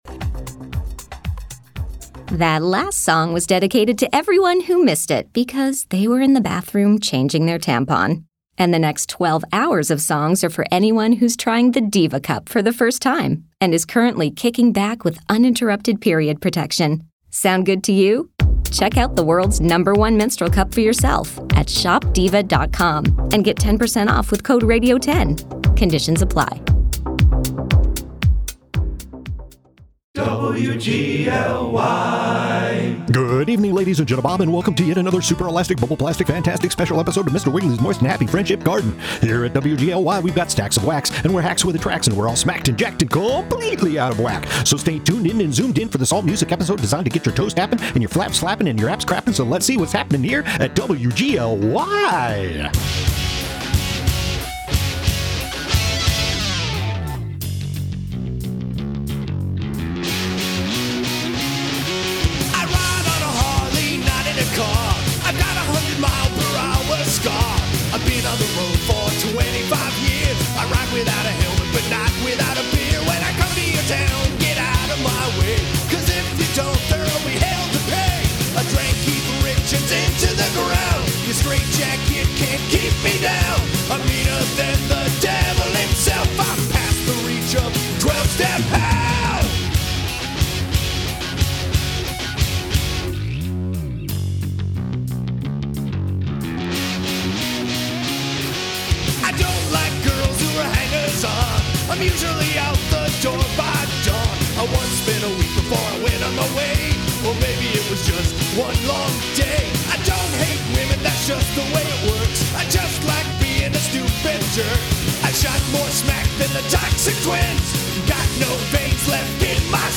SPECIAL EPISODE: The All-Music episode